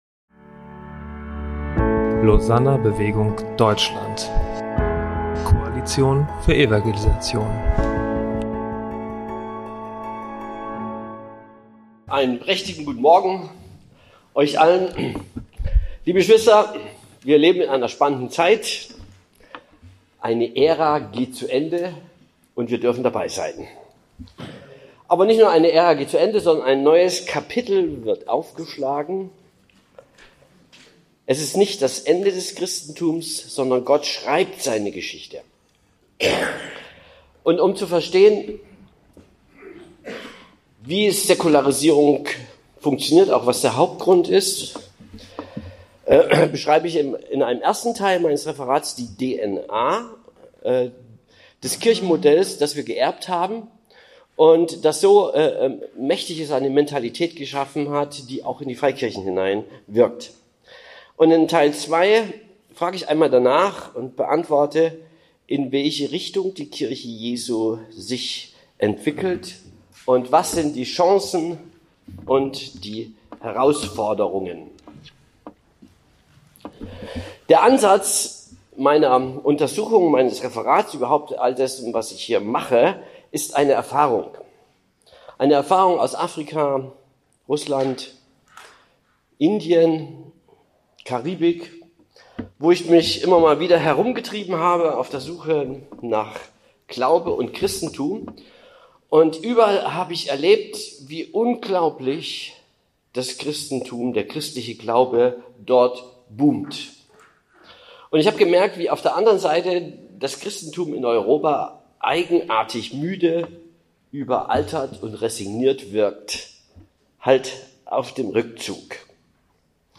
Aufbruch in eine säkulare Welt Eine Ära der Kirche geht zu Ende. Ein neues Kapitel wird aufgeschlagen. Vortrag beim Lausanner Forum 2023 am 10.10.2023 im Refugio in Berlin